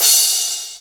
taiko-soft-hitwhistle.ogg